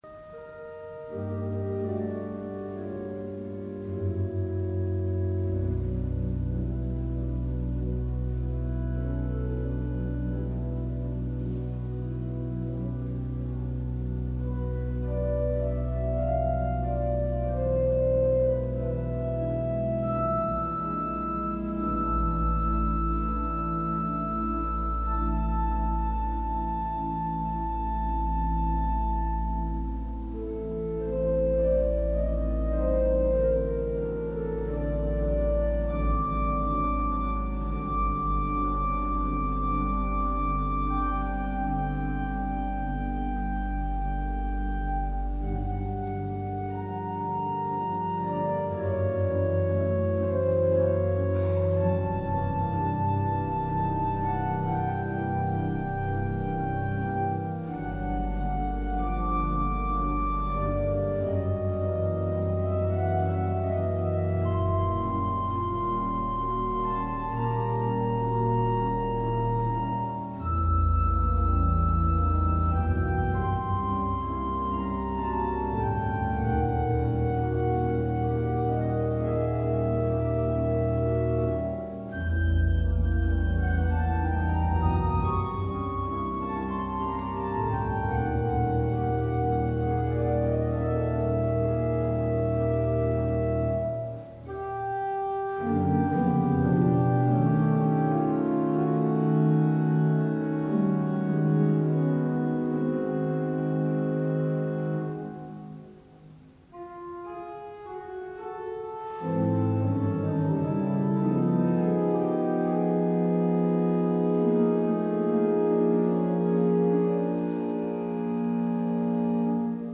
Central-Church-6-21-20-worship-CD.mp3